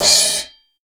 H09CYMB.wav